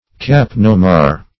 kapnomar - definition of kapnomar - synonyms, pronunciation, spelling from Free Dictionary Search Result for " kapnomar" : The Collaborative International Dictionary of English v.0.48: Kapnomar \Kap"no*mar\, n. (Chem.)